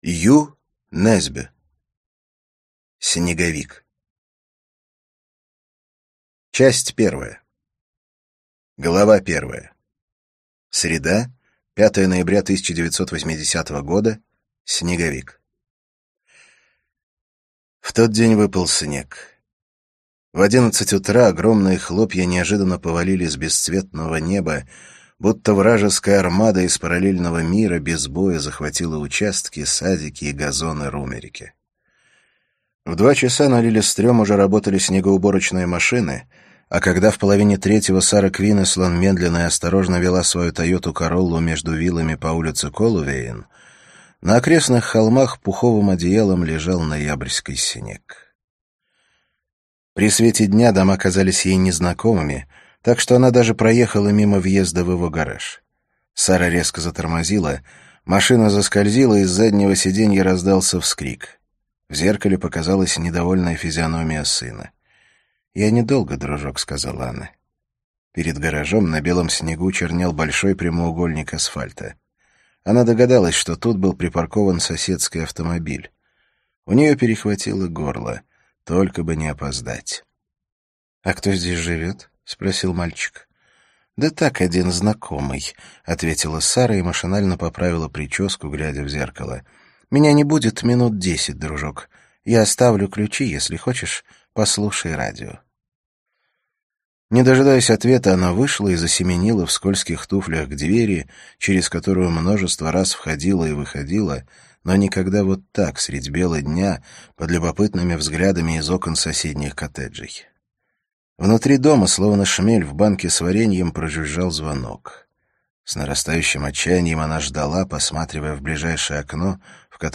Аудиокнига Снеговик - купить, скачать и слушать онлайн | КнигоПоиск